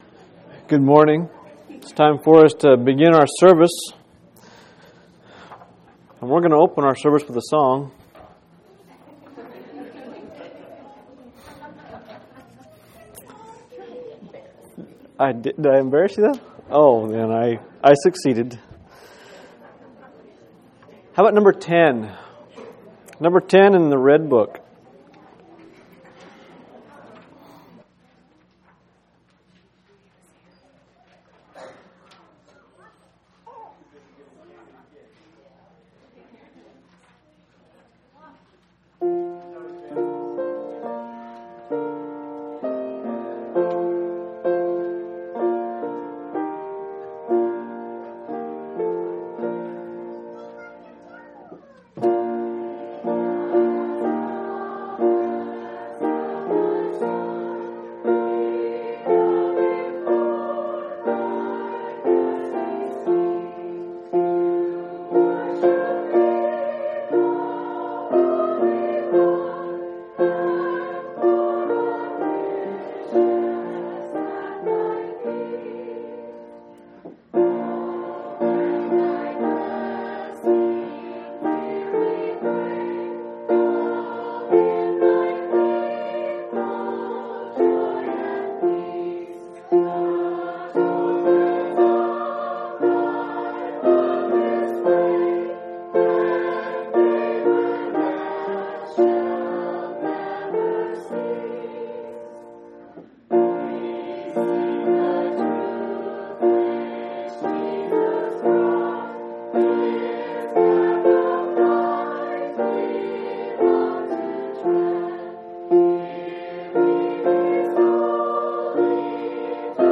10/12/2003 Location: Phoenix Local Event